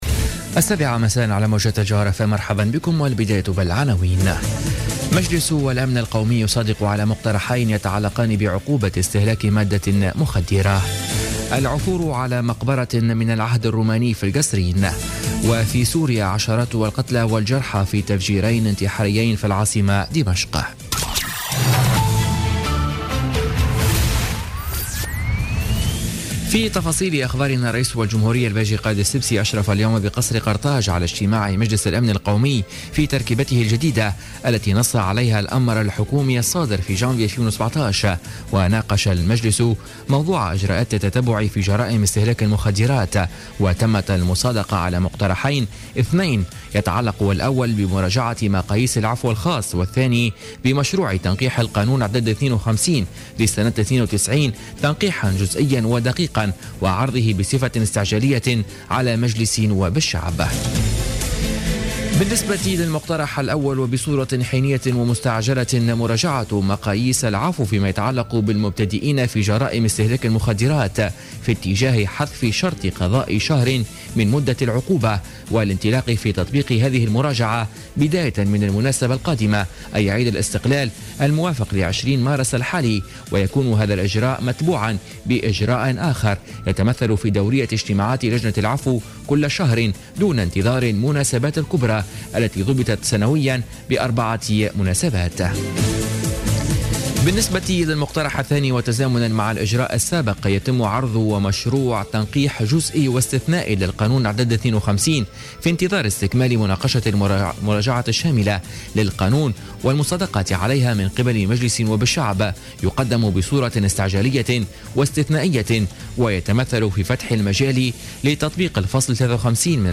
نشرة أخبار السابعة مساء ليوم الأربعاء 15 مارس 2017